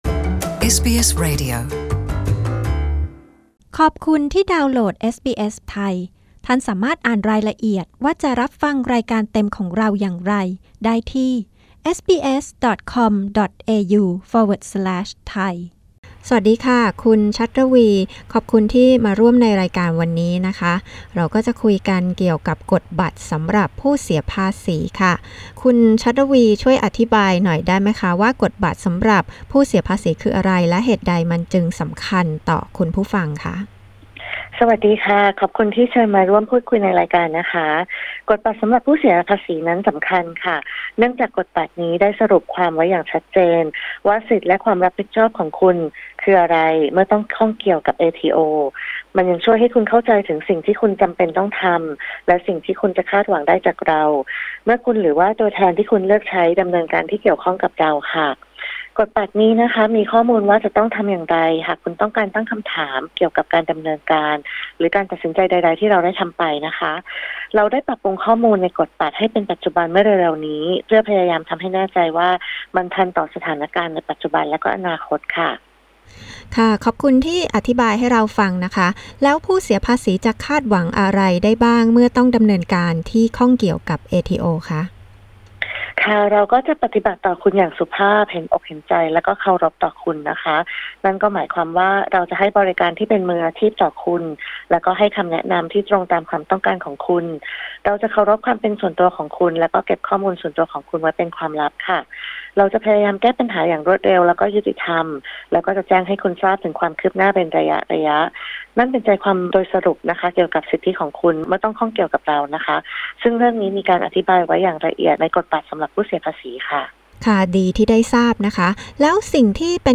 ตัวแทน เอทีโอ อธิบายถึงสิทธิและหน้าที่ของผู้เสียภาษีเมื่อต้องข้องเกี่ยวกับเอทีโอ และสิ่งที่ผู้เสียภาษีจะคาดหวังได้จากการติดต่อไปยังเอทีโอ